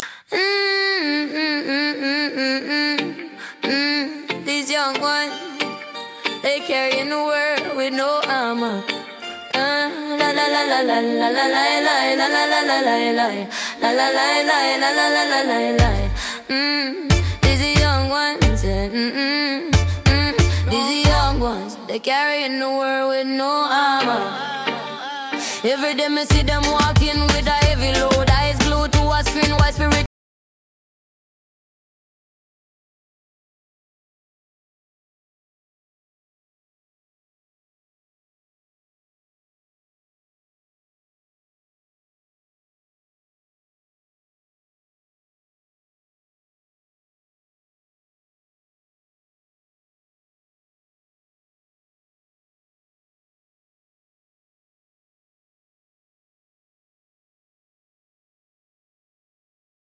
🎧 Genre: Reggae